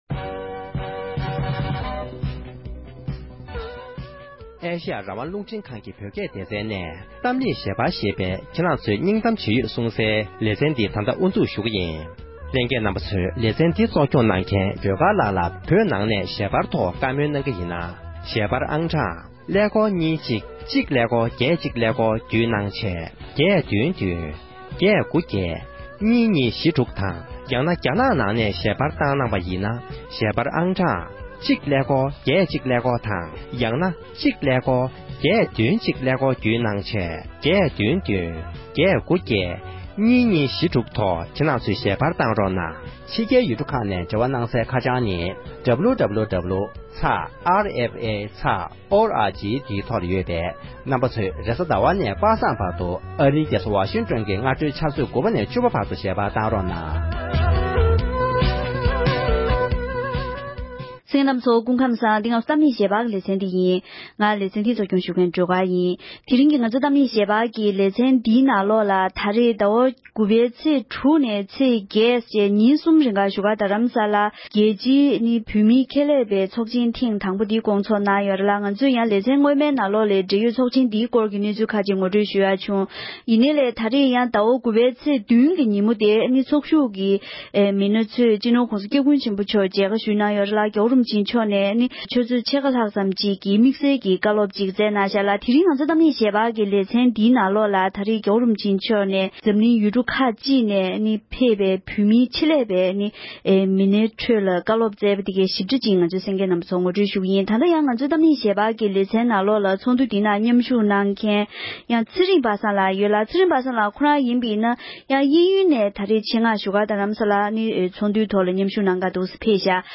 ༸གོང་ས་མཆོགགིས་བོད་མིའི་ཆེད་ལས་མི་སྣའི་ཚོགས་ཆེན་གྱི་ཚོགས་བཅར་བ་རྣམས་ལ་བཀའ་སློབ་བསྩལ་གནང་མཛད་ཡོད་པ།